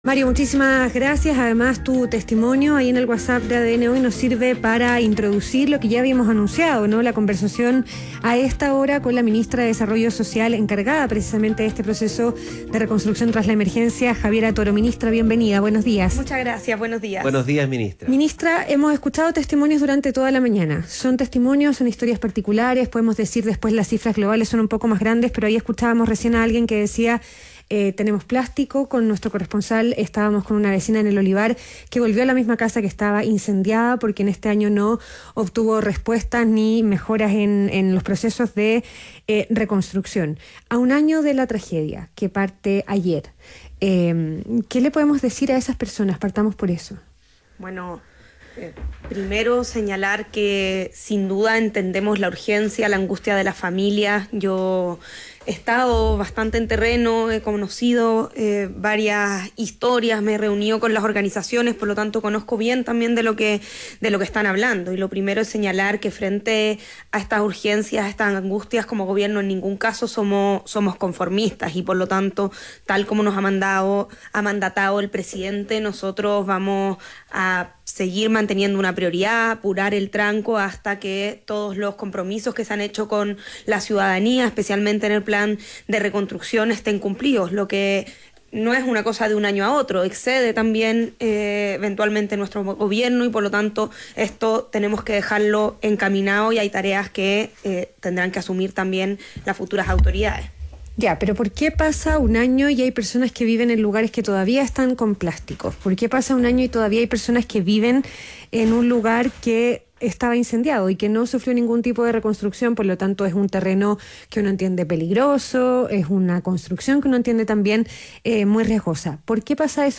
ADN Hoy - Entrevista a Javiera Toro, ministra de Desarrollo Social